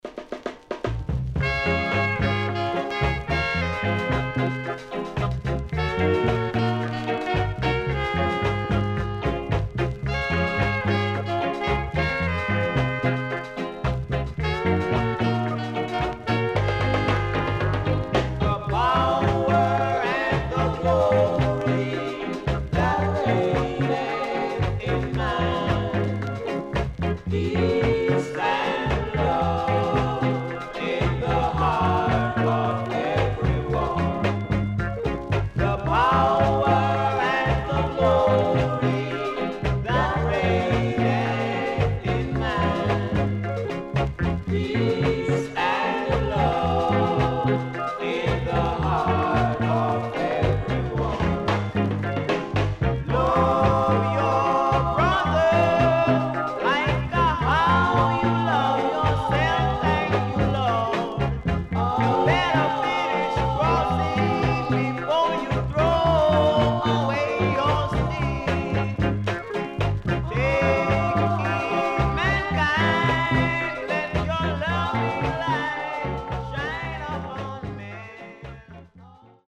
HOME > LP [VINTAGE]  >  EARLY REGGAE
SIDE A:所々チリノイズがあり、少しプチノイズ入ります。